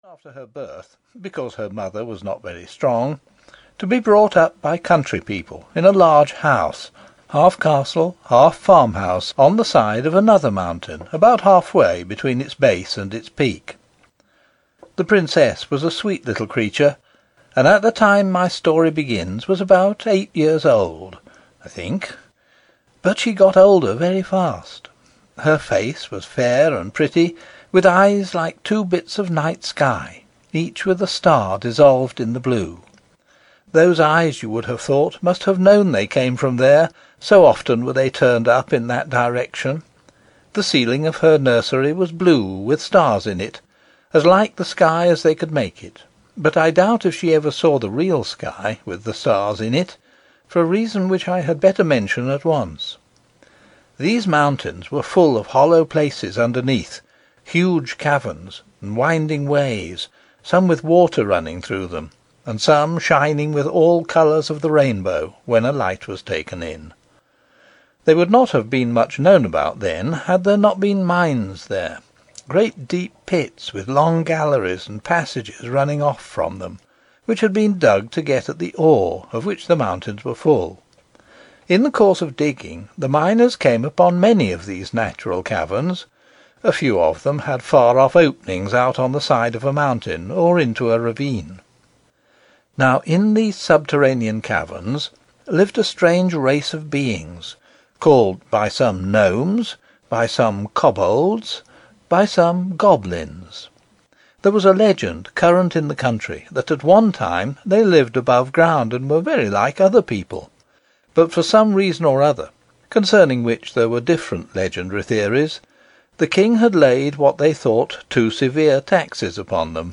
The Princess and the Goblin (EN) audiokniha
Ukázka z knihy